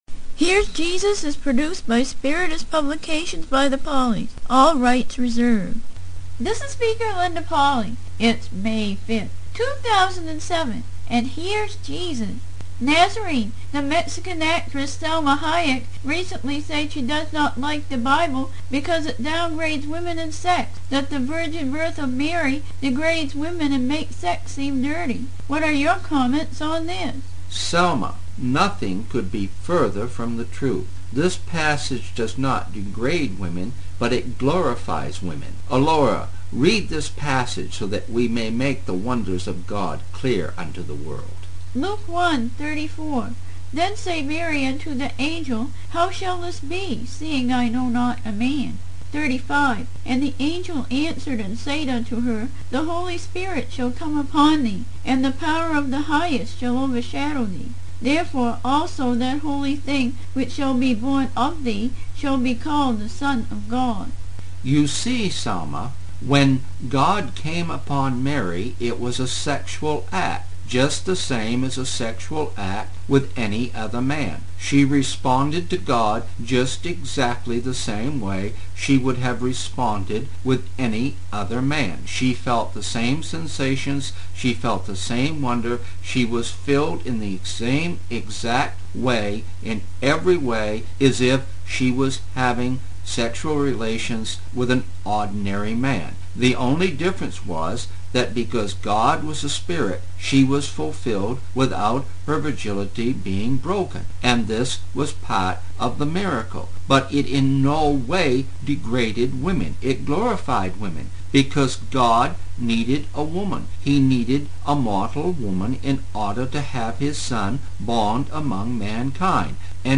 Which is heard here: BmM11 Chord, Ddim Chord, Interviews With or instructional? Interviews With